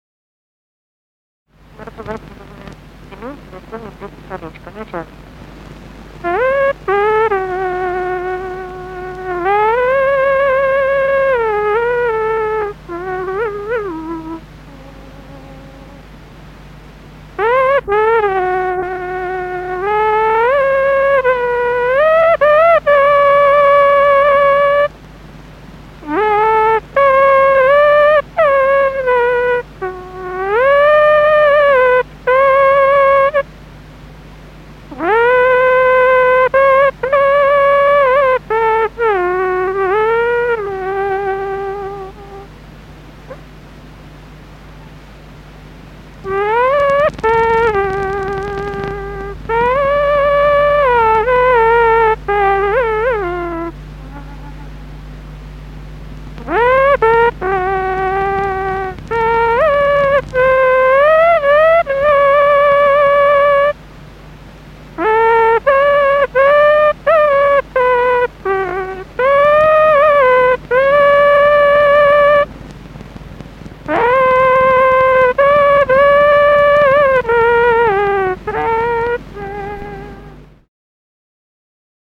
Русские народные песни Владимирской области 6. Потеряла я колечко (лирическая) с. Муромцево Судогодского района Владимирской области.